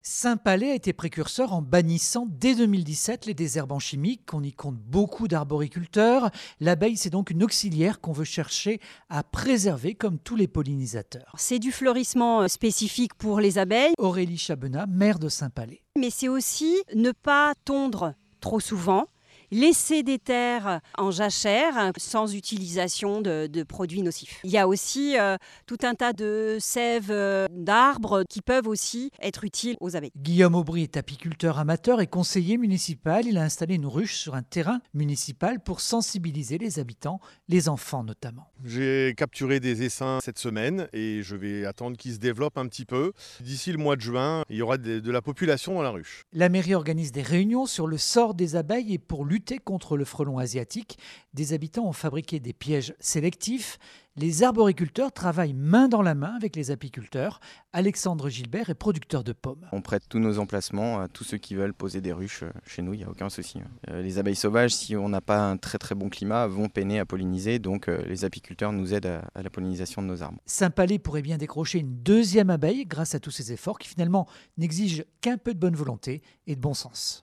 Reportage-2 France Bleu Berry – mai 2024